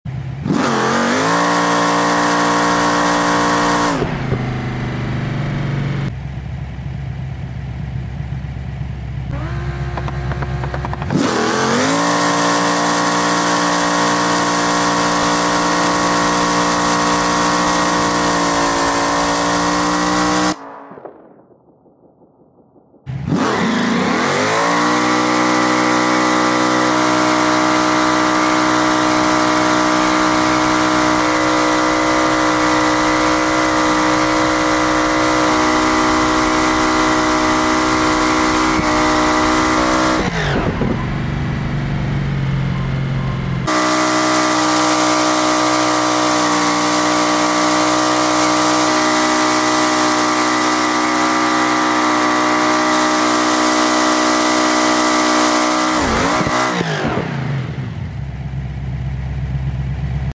Big Block V8 Swapped Fiat sound effects free download